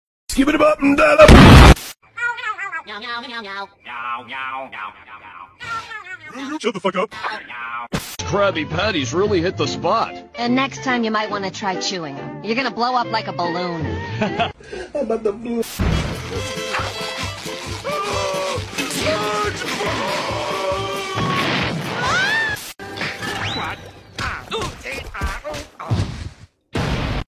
Top 5 FunniestSpongebob Explosion Moments sound effects free download